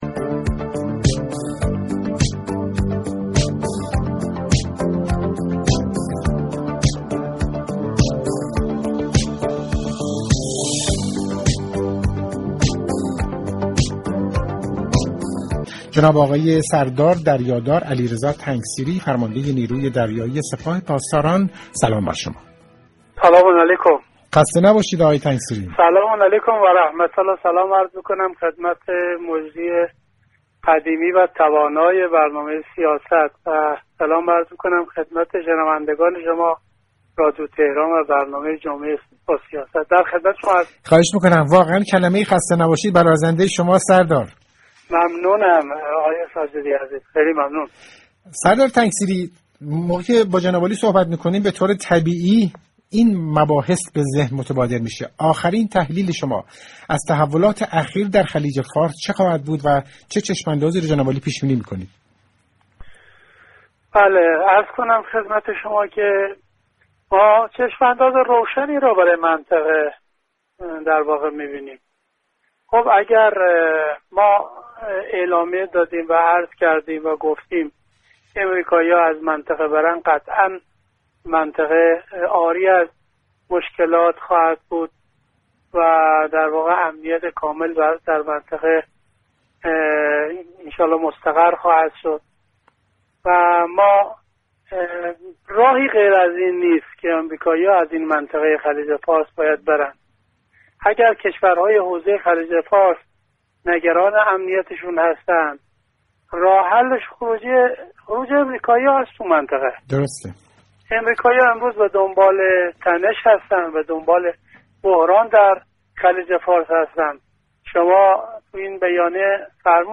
فرمانده نیروی دریایی سپاه پاسداران انقلاب اسلامی در برنامه جمعه با سیاست درباره مواجهه اخیر قایق‌های تندروی این نیرو با ناوهای آمریكایی در خلیج فارس گفت: آنها وارد حریم منطقه تمرین ما شده بودند، برای همین به آنها دستور داده شد تا خارج شوند.